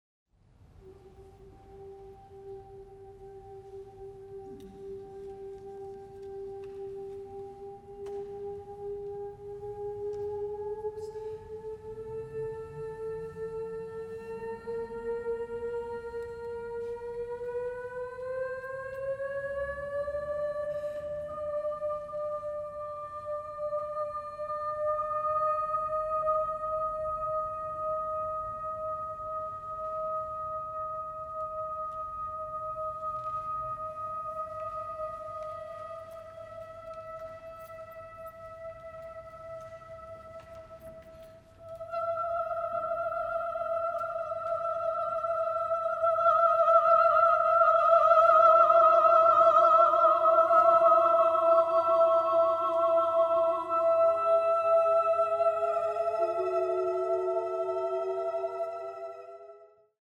Rīgas Vecajā Svētās Ģertrūdes baznīcā
Žanrs: Kormūzika
Instrumentācija: jauktajam korim